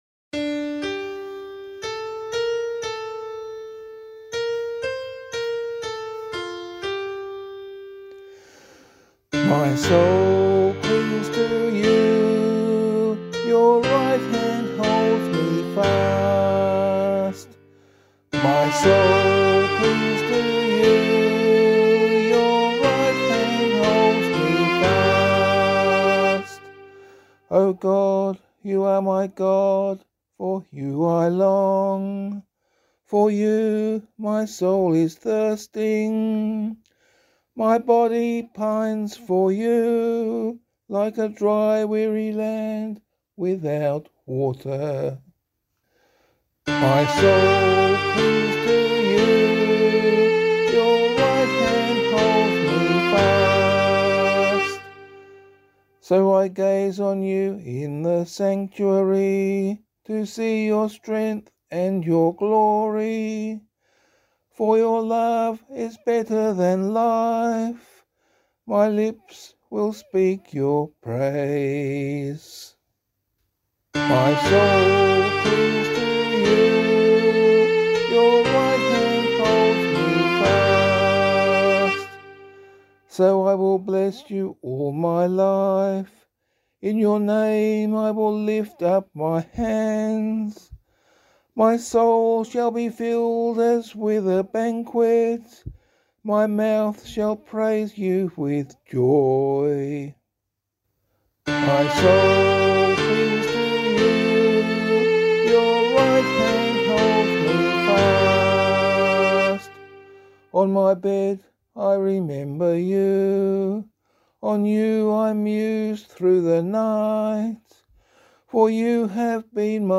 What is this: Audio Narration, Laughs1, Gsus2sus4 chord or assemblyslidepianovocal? assemblyslidepianovocal